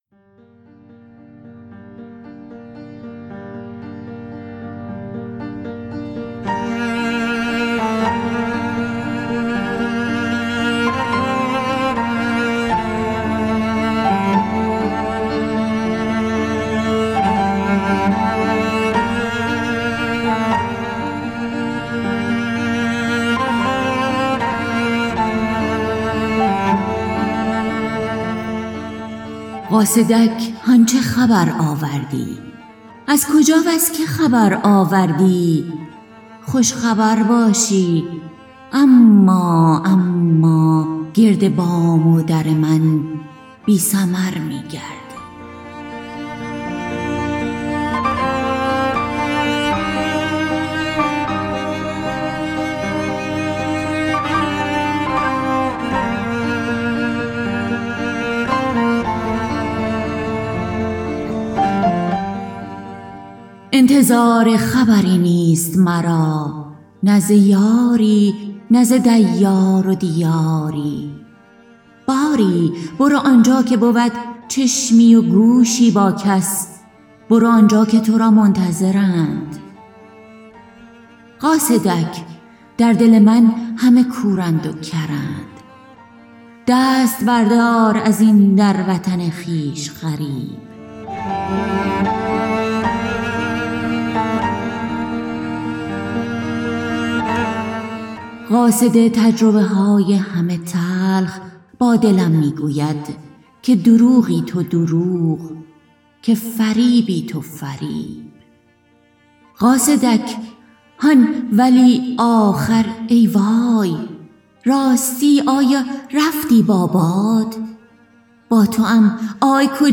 شعر خوانى برنامه اى از رادیو فرهنگ